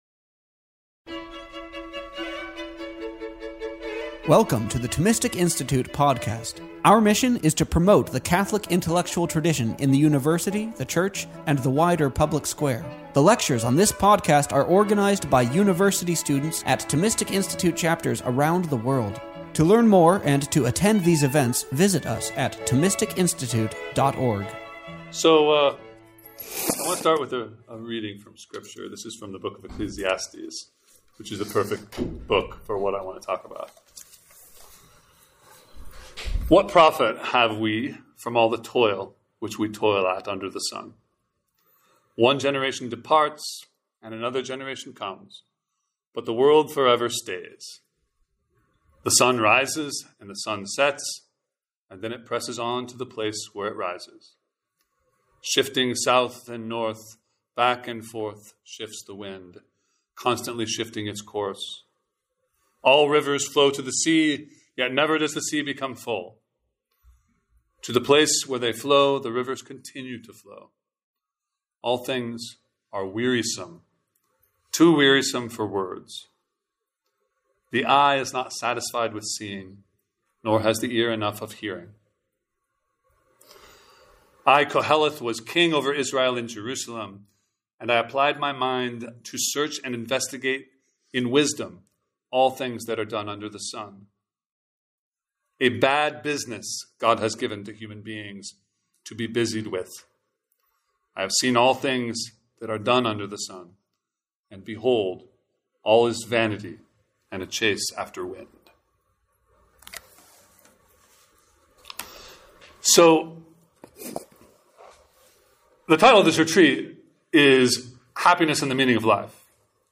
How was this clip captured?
This lecture was given on April 20th, 2024, St. Albert's Priory.